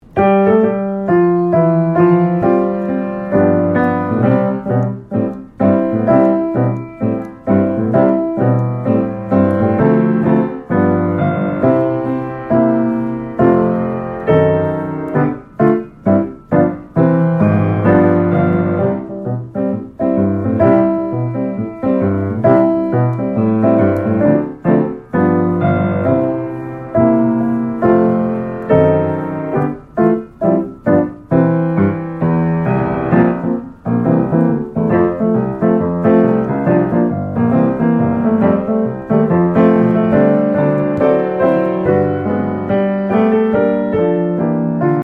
Listen to a sample of the instrumental track.
▪ The full-length piano accompaniment music track.